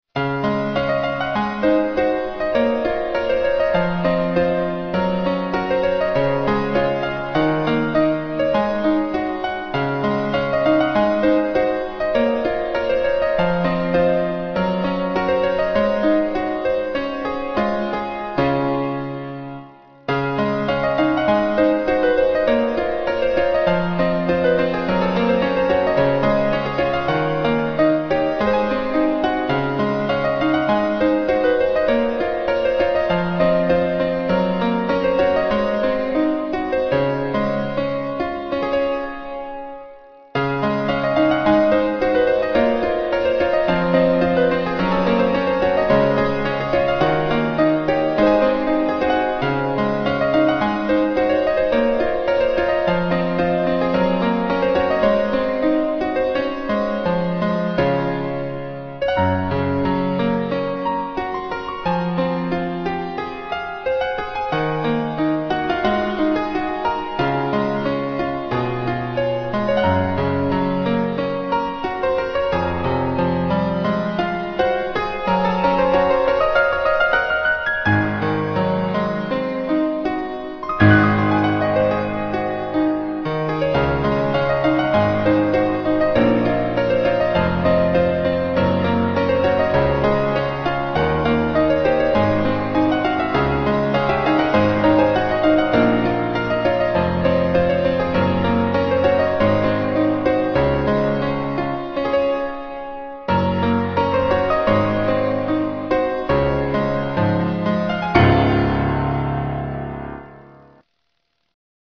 • 【促销音乐】纯音乐 - 适合提示音配乐的欢快